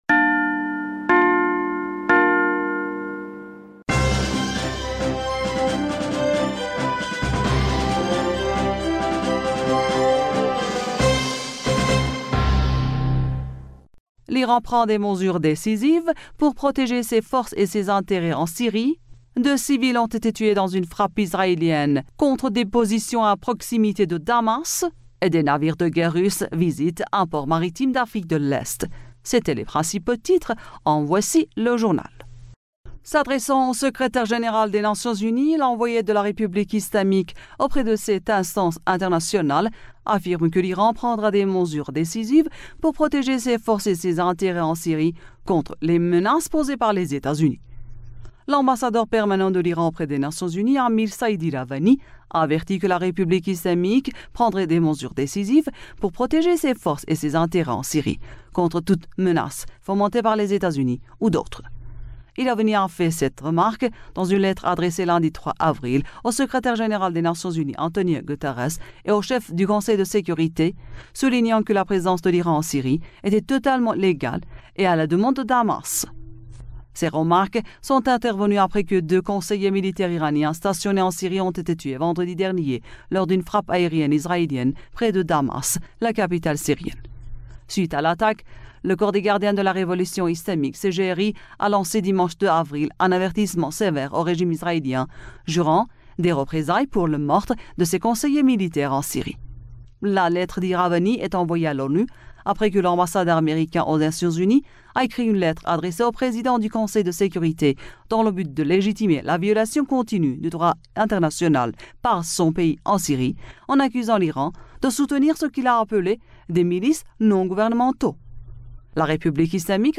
Bulletin d'information du 04 Avril 2023